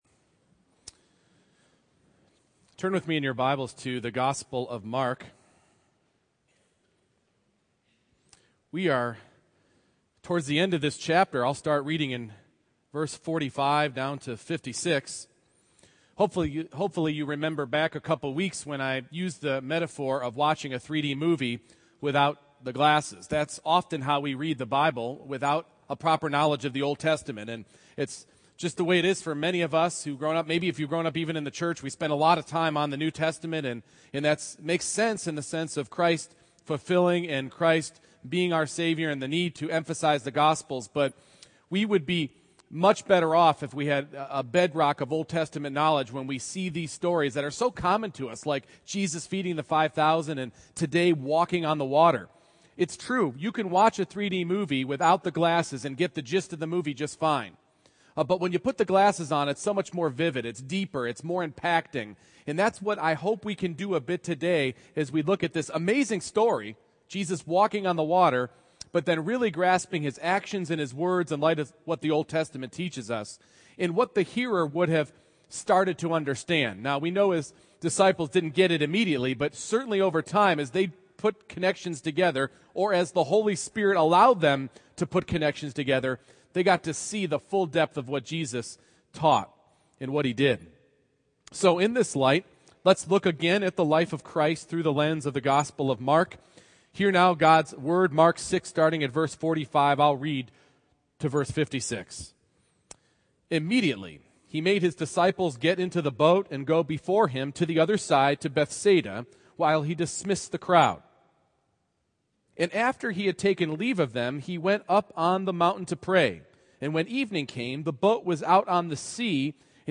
Service Type: Morning Worship